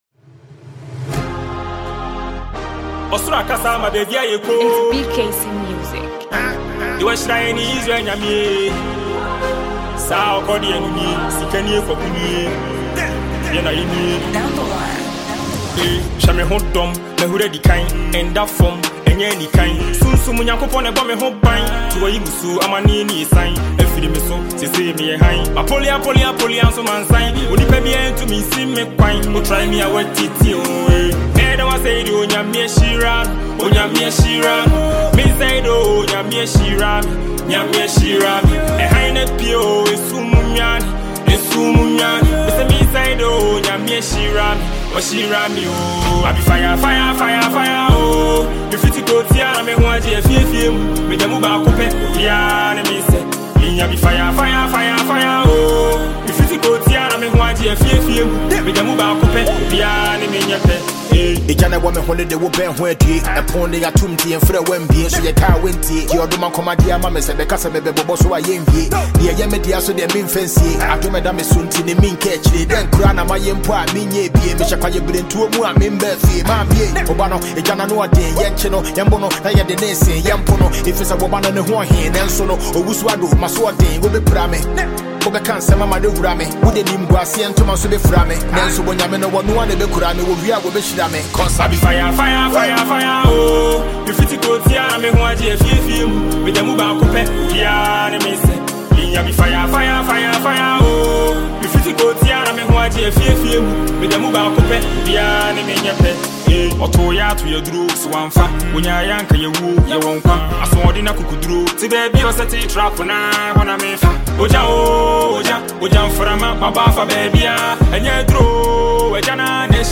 Ghanaian talented songwriter and singer
a free mp3 song featured top class Ghanaian rapper